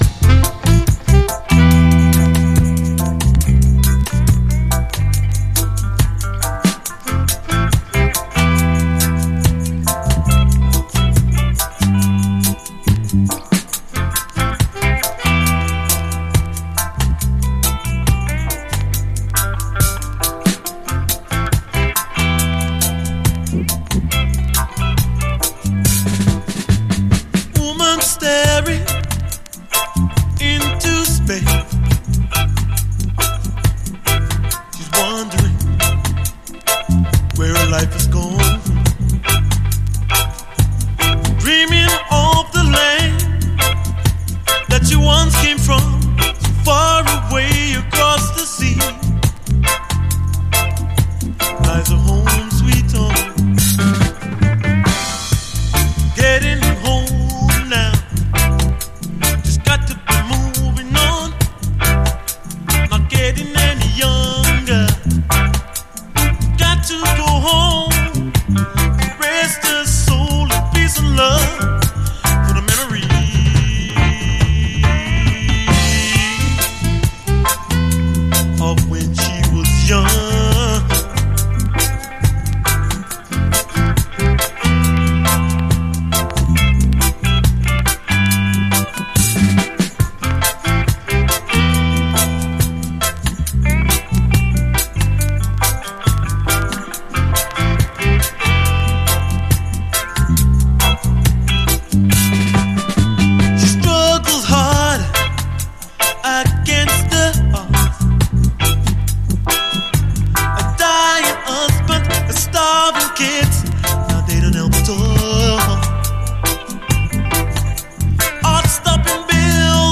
REGGAE
特にB面の悩ましい哀愁メロウ・レゲエ
派手な曲ではないですがUKルーツ特有の苦みばしったメロウネスが沁みるオススメの一曲！